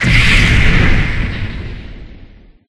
Wind10.ogg